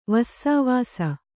♪ vassāvāsa